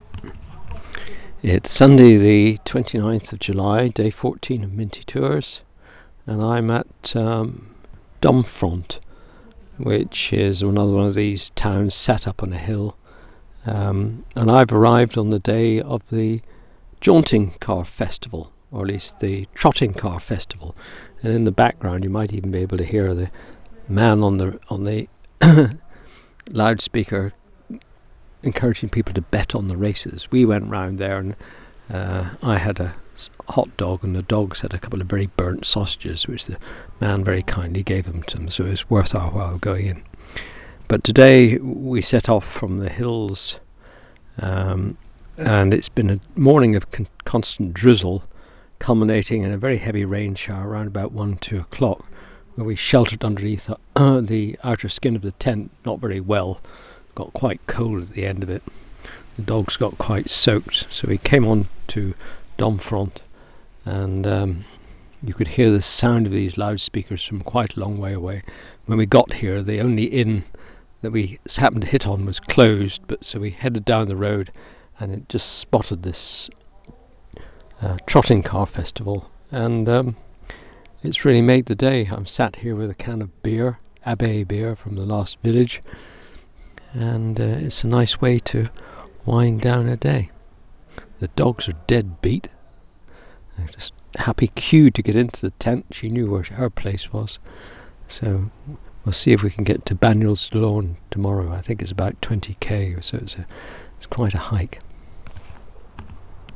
Arrived in Dromfront to find a trotting cart race in full swing.
There was a band playing circular trumpets thar sounded like a Brazilian football match!